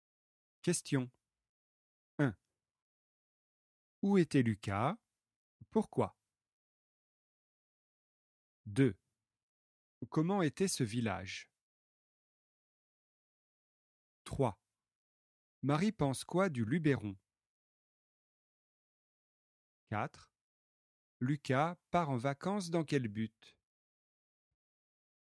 🔷 DIALOGUE